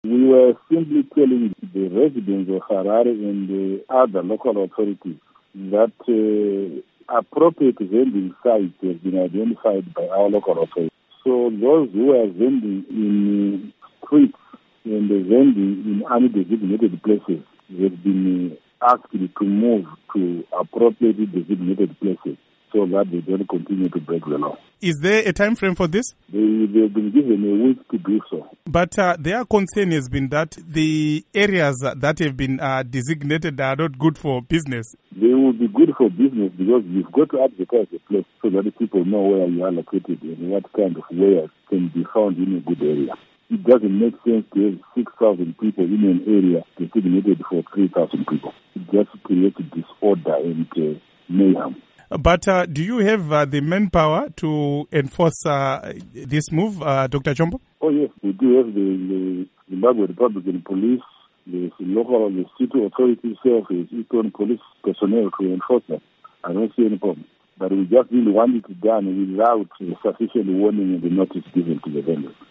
Interview With Ignatius Chombo on Vendors